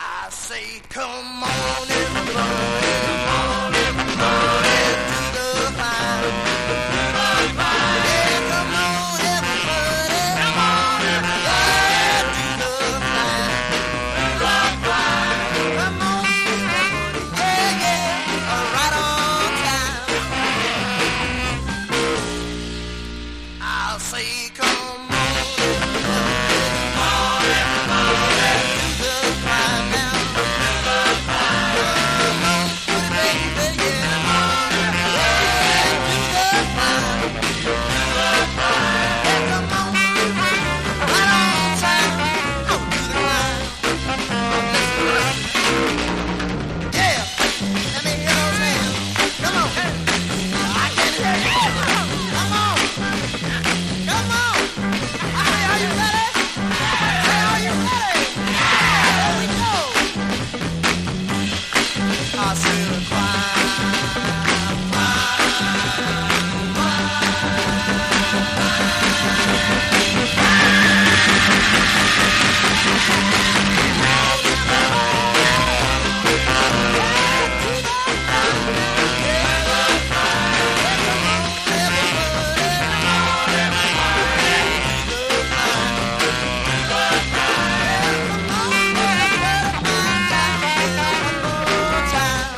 フラットロックの王様！